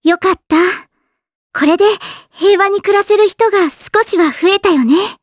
Kirze-Vox_Victory_jp.wav